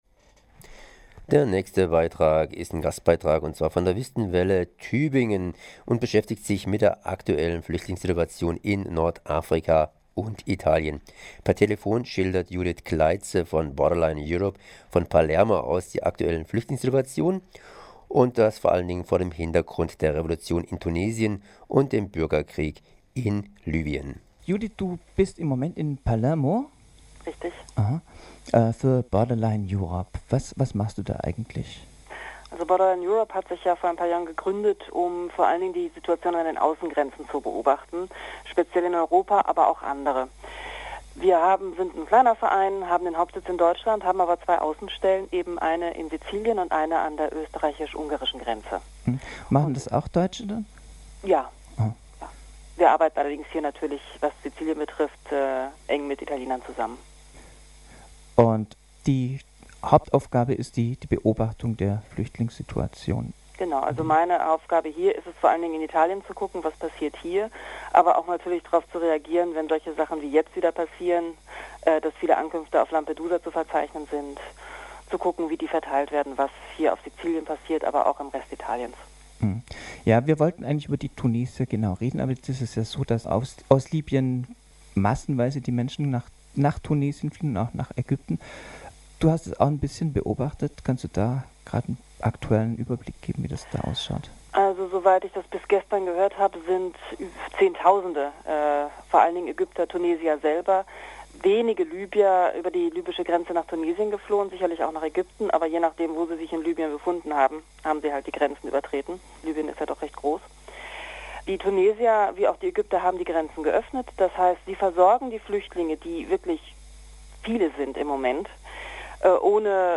Per Telefon